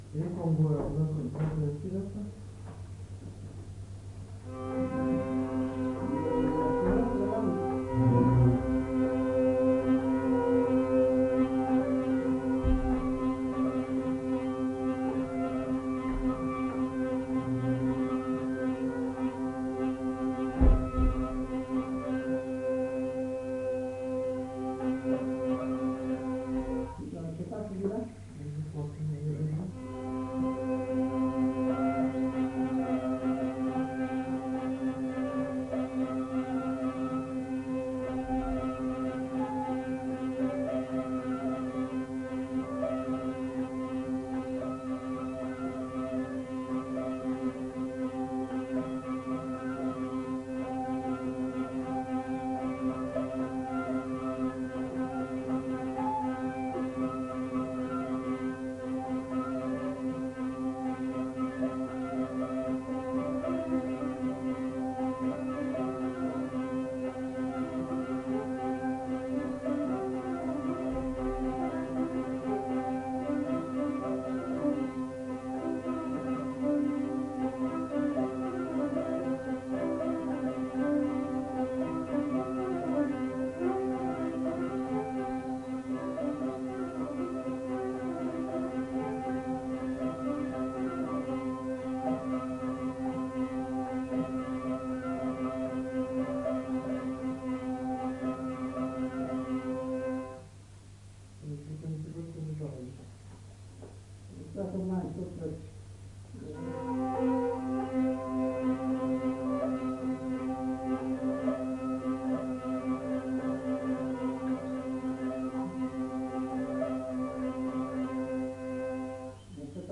Aire culturelle : Petites-Landes
Lieu : Lencouacq
Genre : morceau instrumental
Instrument de musique : vielle à roue ; violon
Danse : congo